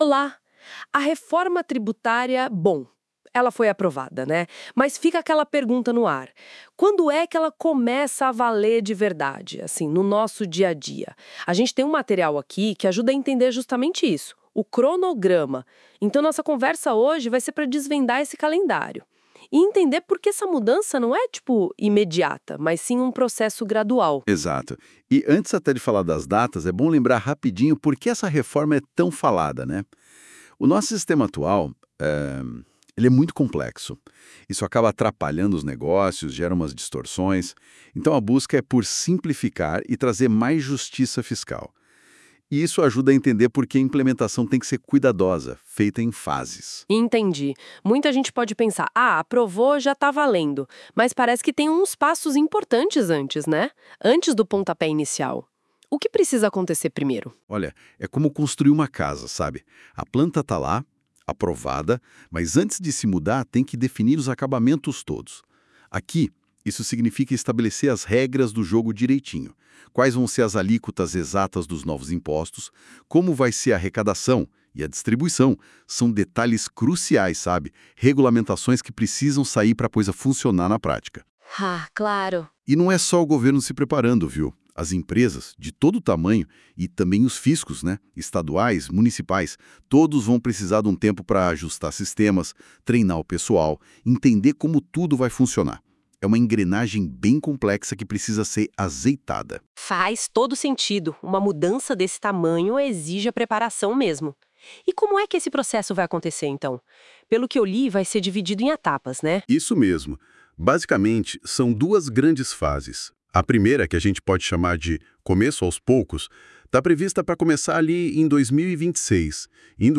Quer entender isso em formato de bate-papo?